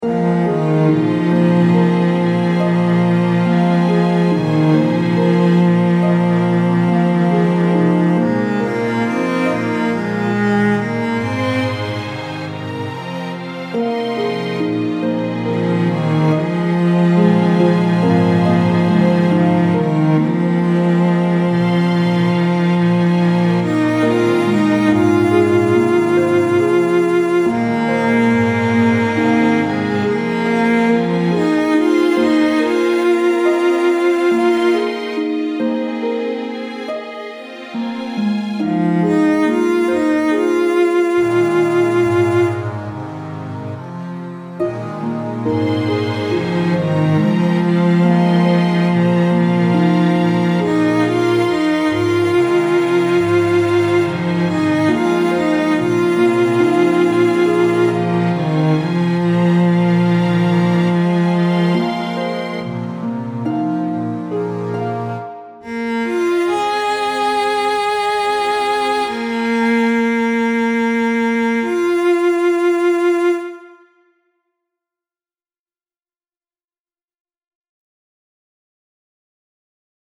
Many of the songs labeled “digital” were composed using transformations of cellular automata in Mathematica.